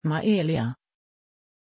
Prononciation de Maélia
pronunciation-maélia-sv.mp3